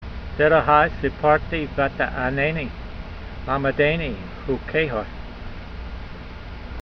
Sound (Psalm 119:26) Transliteration: der(d)a h ai see par tee va ' ta 'a' nay nee la'me' day nee hu k ey ha Vocabulary Guide: I have declared my ways (habits and desires) and you responded unto me : teach me your statute s (righteousnesses) . Translation: I have declared my ways (habits and desires) and you responded unto me: teach me your statutes (righteousnesses) .